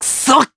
Neraxis-Vox_Damage_jp_02.wav